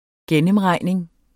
Udtale [ -ˌʁɑjˀneŋ ]